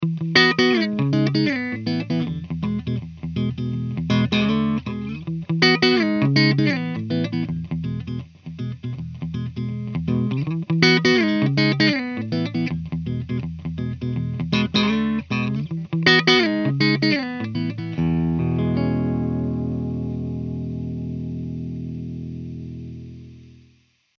Pop rhythm